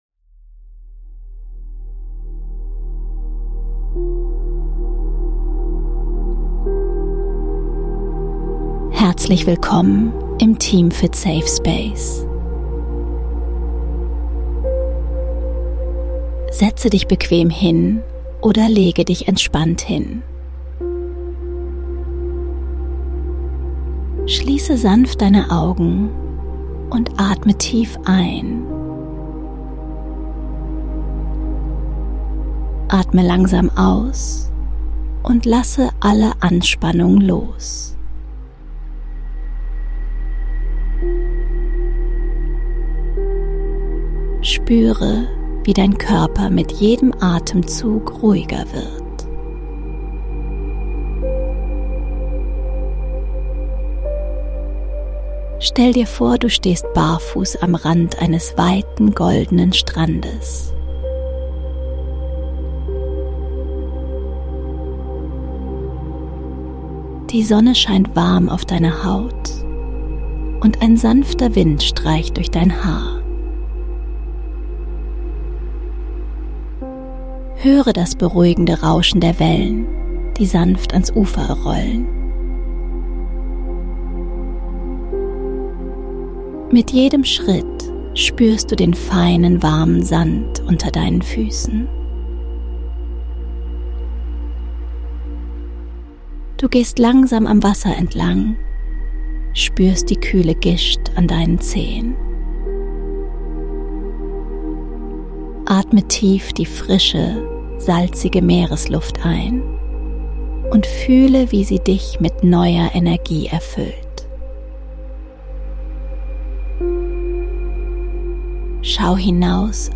Eine entspannende Traumreise, die dich auf einen ruhigen